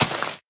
1.21.4 / assets / minecraft / sounds / dig / grass4.ogg
grass4.ogg